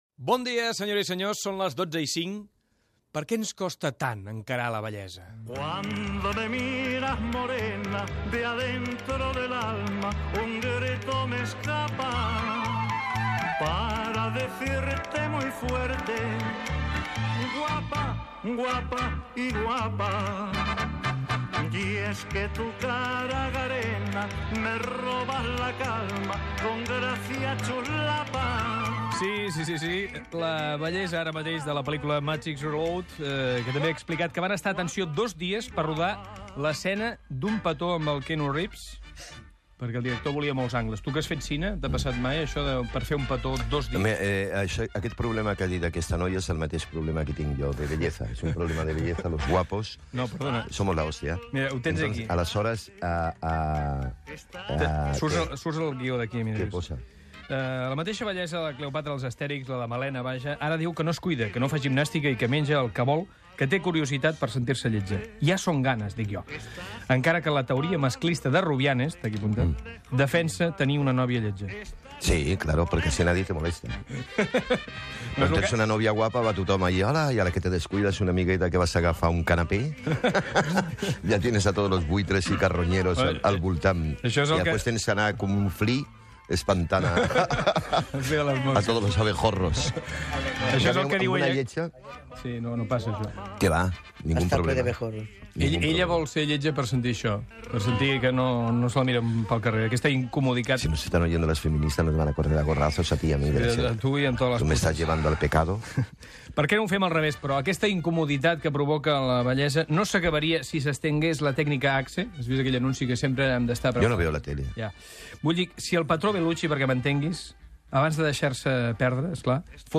La bellesa, conversa amb l'actor Pepe Rubianes
Entreteniment